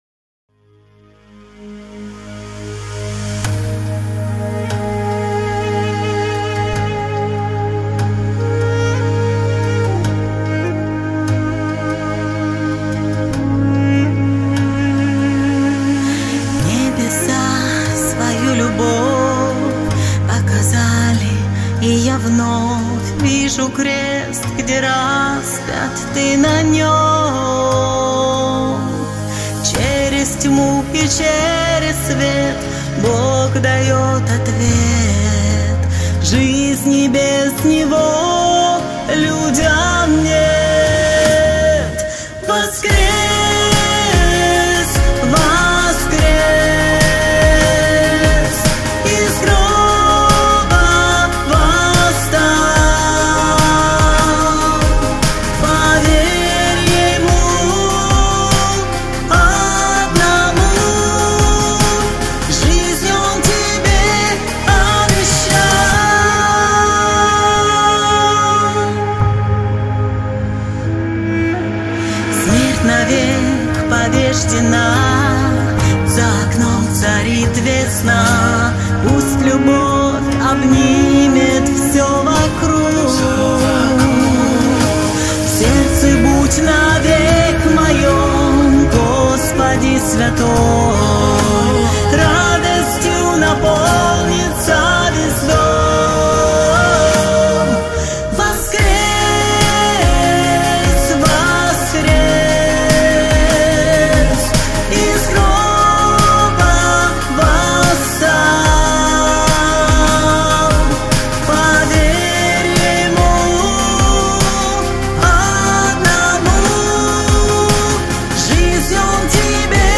455 просмотров 736 прослушиваний 140 скачиваний BPM: 73